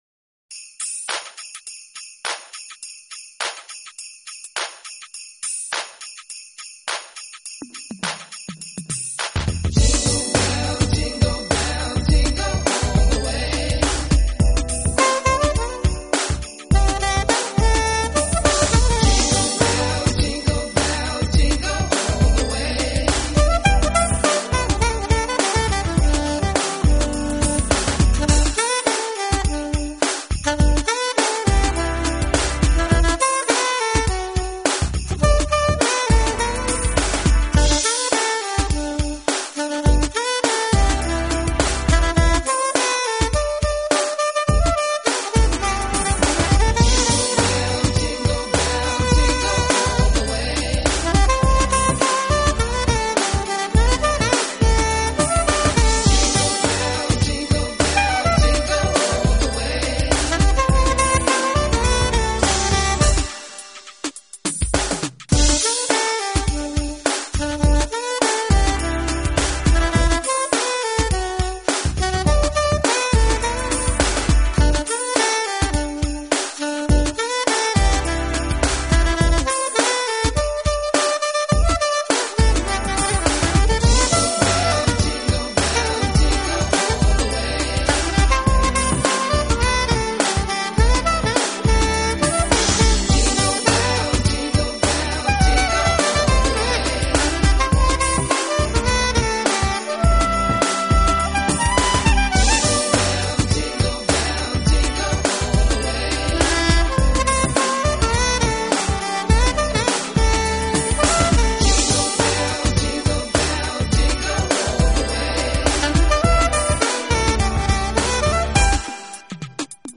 风格：Smooth Jazz, R&B
精纯的sax演奏下，耳熟能详的曲目更显醇厚温柔，构成圣诞新年的一道听觉佳肴！